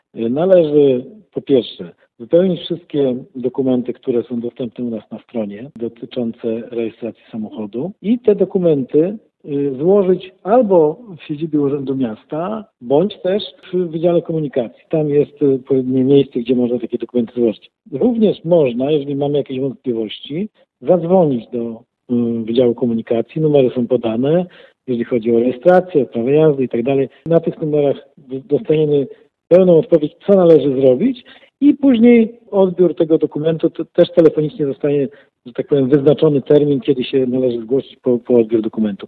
Jak zarejestrować samochód, który sprowadziłem z zagranicy? Pyta słuchacz, który zadzwonił do naszej redakcji.